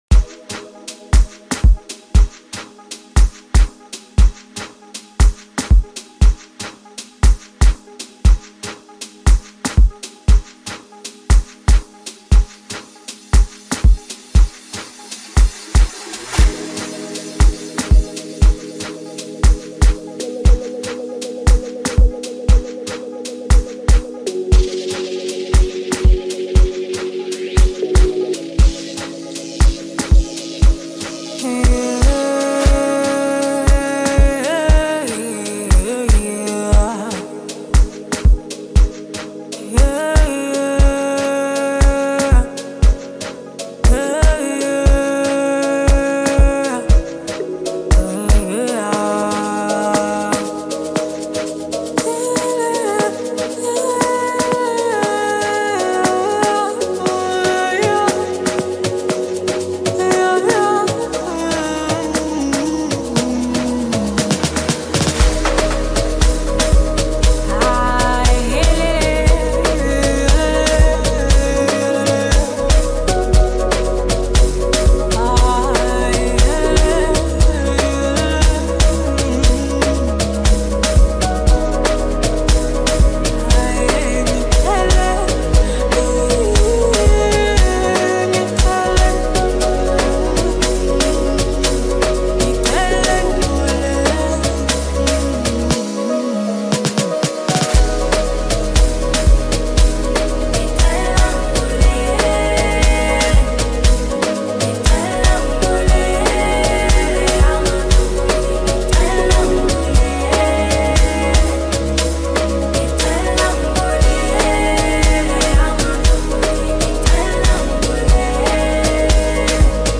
Home » Amapiano » DJ Mix » Hip Hop
South African singer-songwriter